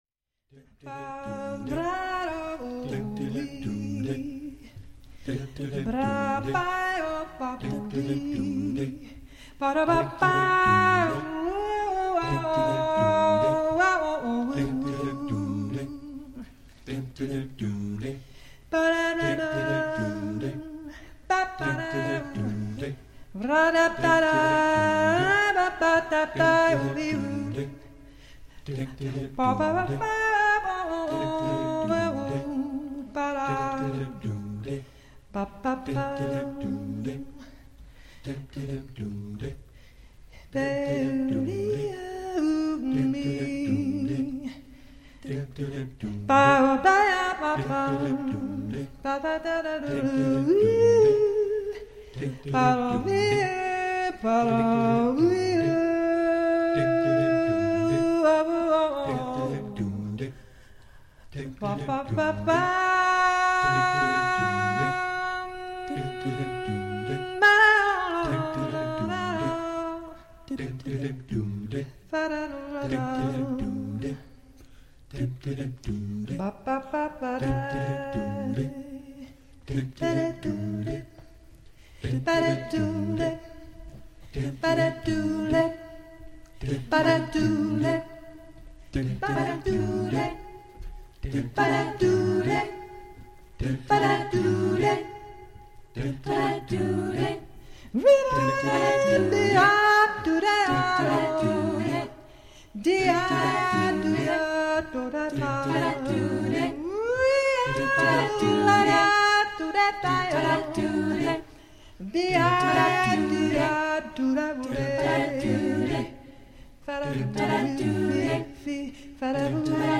IMPROVISATION
5 nights of spontaneous composition with 17 singers.